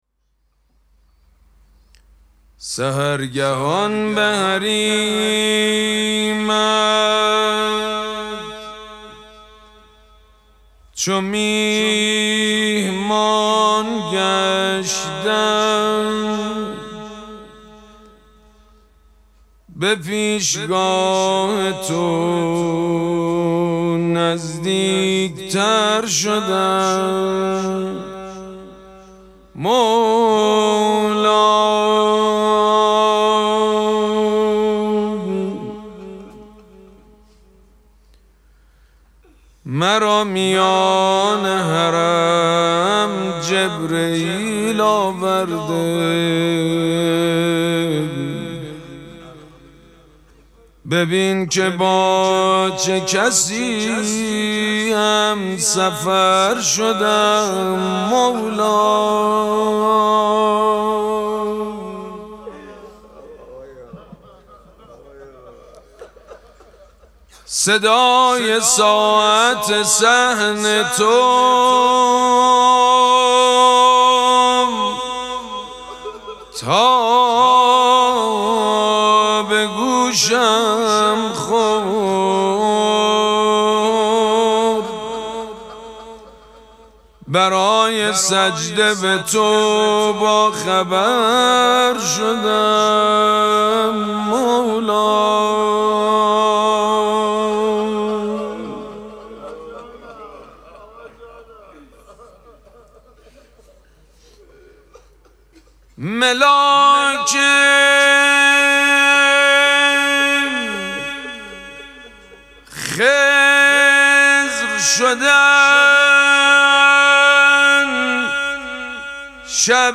مراسم مناجات شب ششم ماه مبارک رمضان
حسینیه ریحانه الحسین سلام الله علیها
مناجات
حاج سید مجید بنی فاطمه